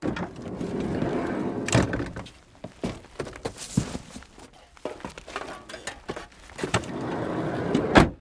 wreck_van_1.ogg